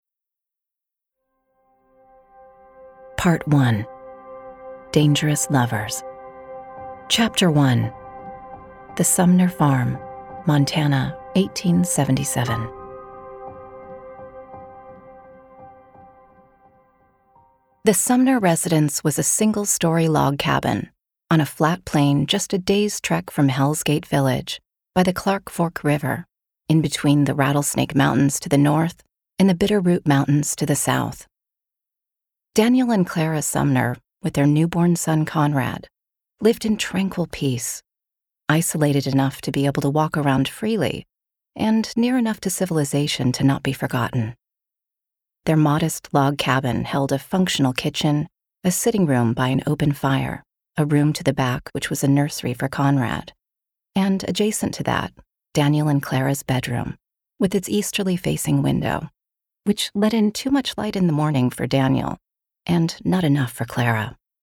Audiobook narration "Maria and the Devil" by Graham Thomas